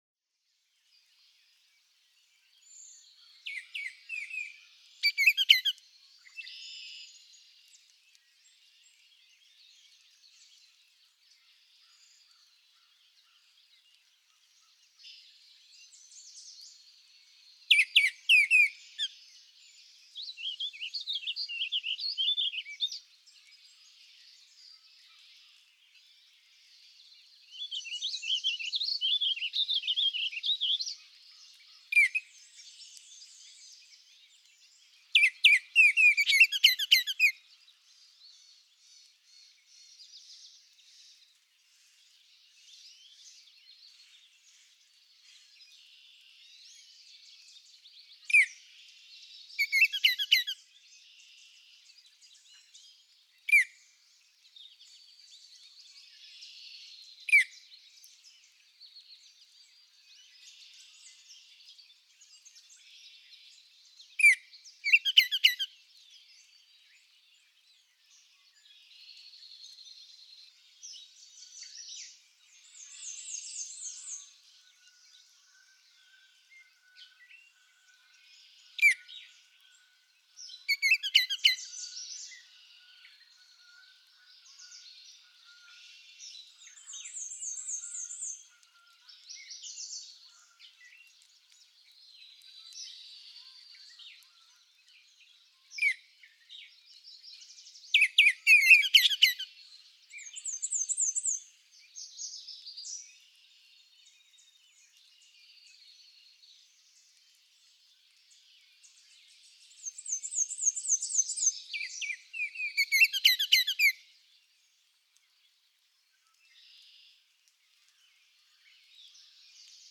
Baltimore oriole
Multiple male-female song exchanges excerpted from several hours on a May morning. She often sings a complete, complex song from the nest (we-chew-dle-wee-dle-wee-dle-weet); he sometimes offers his complete song, tew tew twe twe, two low notes followed by two high notes, but often sings only one or two of the low notes. Accompanying the orioles are a warbling vireo (e.g., 0:20, 0:27) and a black-and-white warbler (six songs best heard from 1:50 to 2:51).
Norwottuck Rail Trail, Amherst, Massachusetts.
054_Baltimore_Oriole.mp3